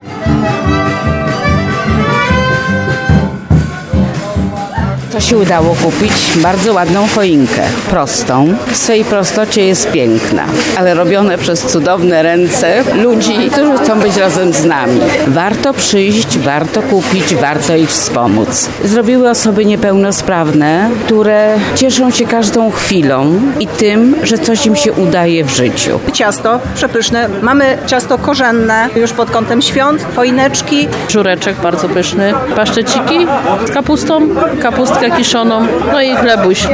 Tradycyjne smaki i zapachy królujące na straganach, czyli Dzień Kupca w Tarnowie